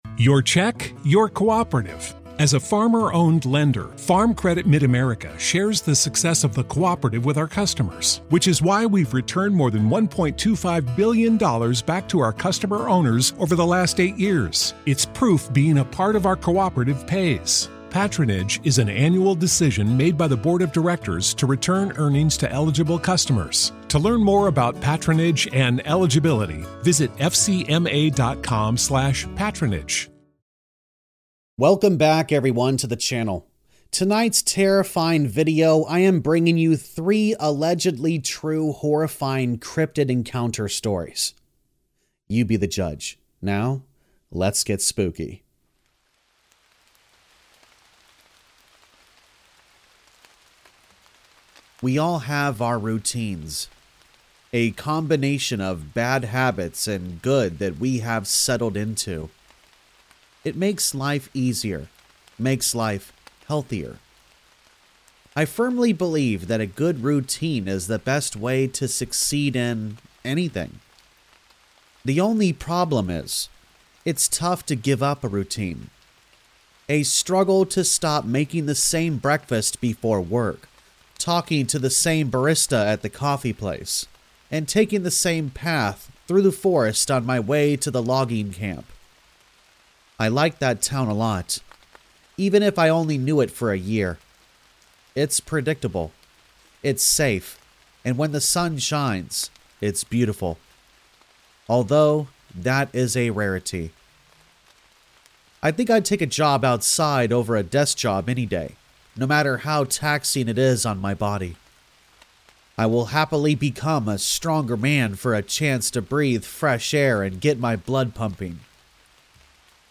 3 TRUE Cryptid Horror Stories | Scary Stories With Rain Sounds
All Stories are read with full permission from the authors: